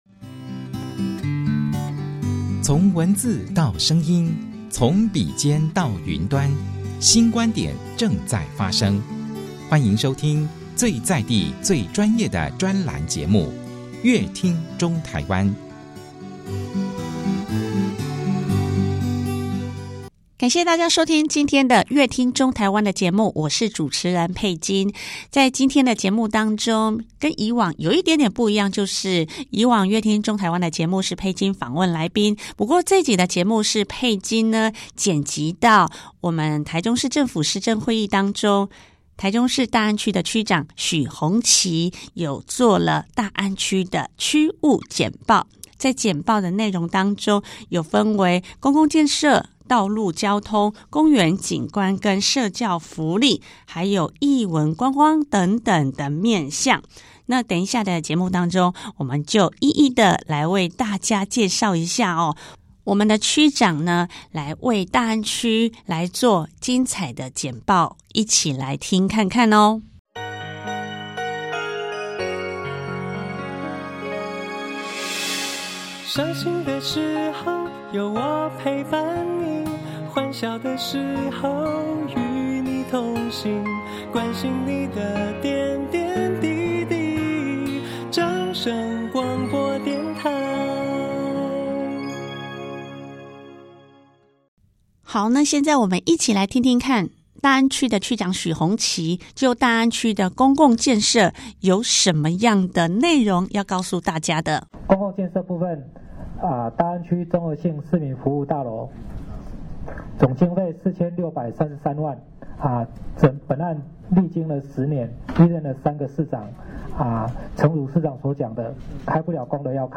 富市臺中 樂活大安 許宏綺區長就五個面向(公共建設、道路交通、公園景觀、社教福利、藝文觀光)來介紹大安區，許區長也語重心長感謝市長與市府團隊的協助，讓大安區許多的公共建設得以繼續推動。